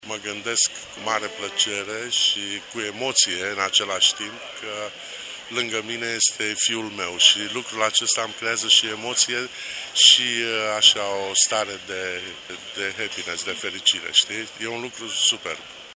Acesta a mărturisit în cadrul unui interviu acordat pentru Radio România Brașov FM că dacă ar da timpul înapoi ar urma exact aceiași pași profesionali